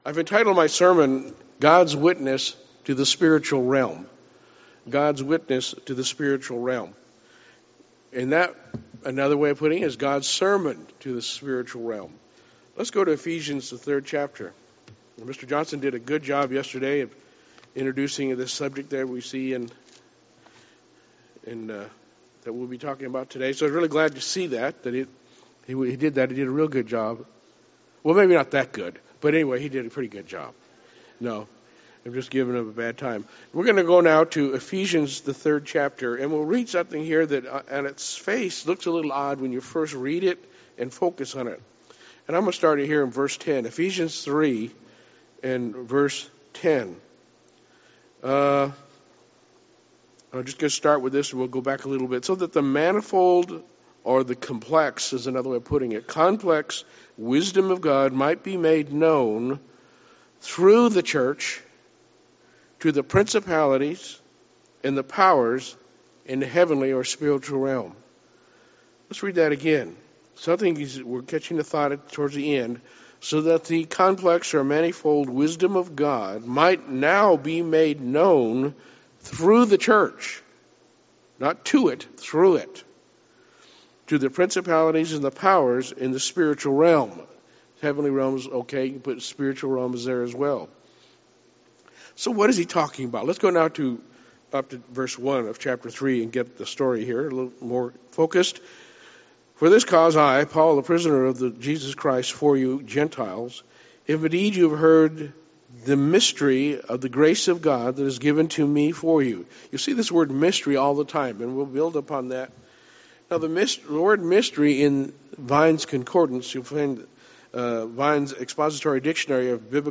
This sermon was given at the Branson, Missouri 2022 Feast site.